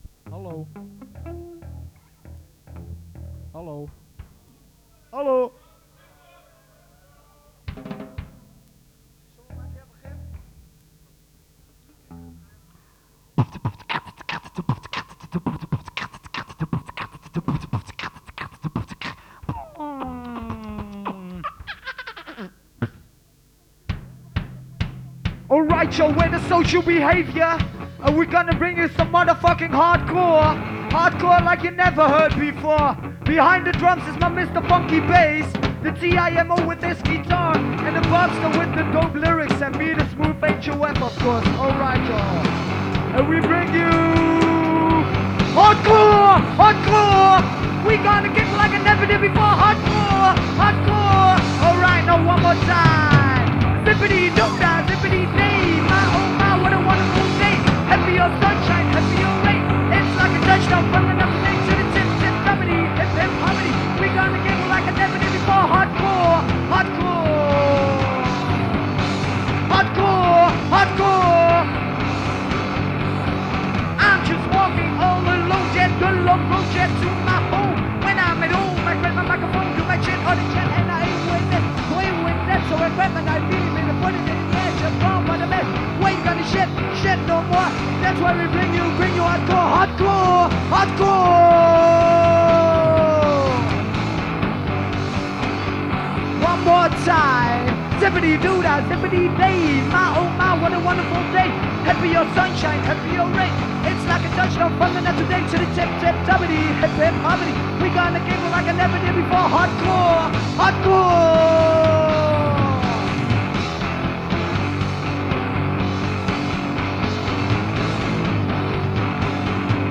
Live in Atak Enschede